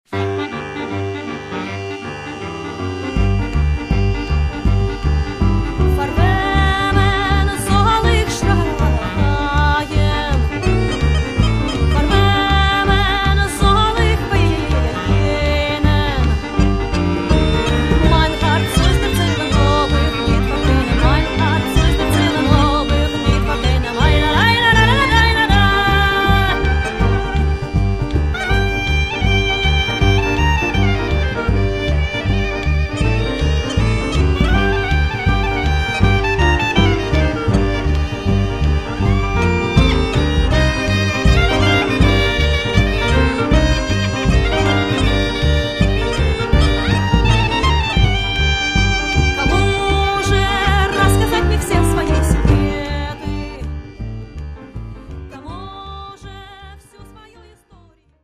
clarinetista
gaita